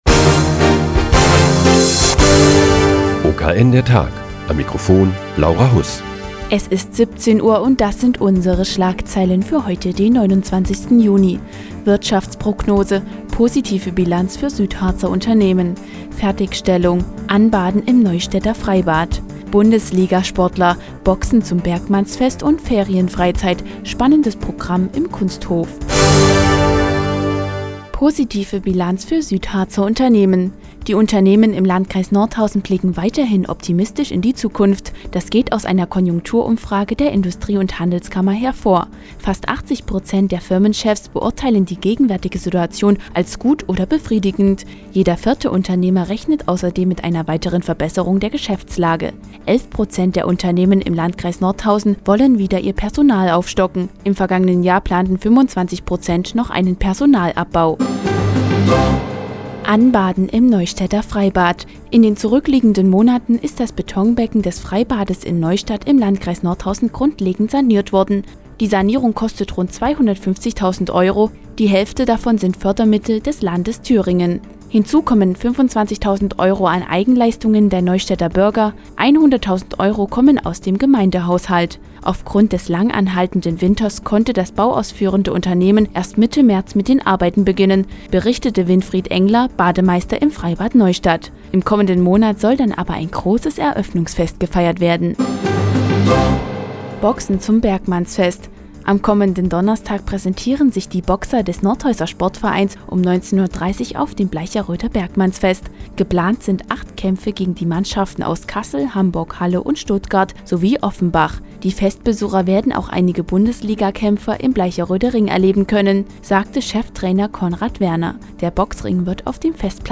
Die tägliche Nachrichtensendung des OKN ist nun auch in der nnz zu hören. Heute geht es um Anbaden im Neustädter Freibad und die Ferienfreizeit für Kinder im Kunsthof Friedrichsrode.